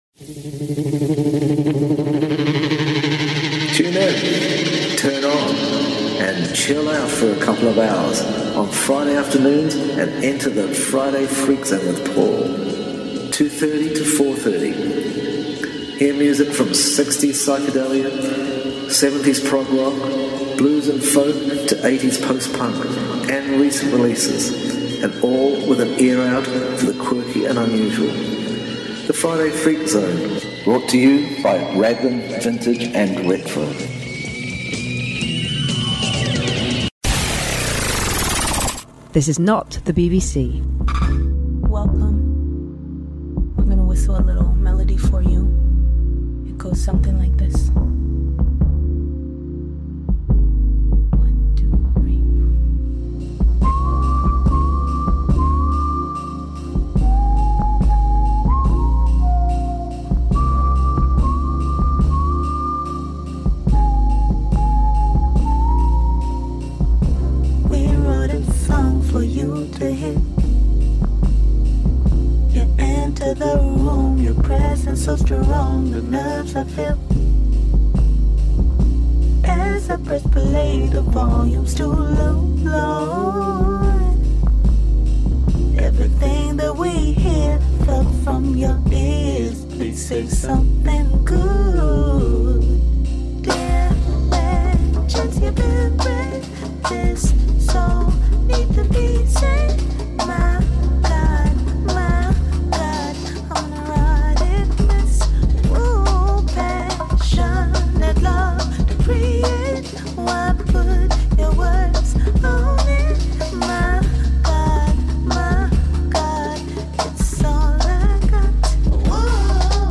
late summer grooves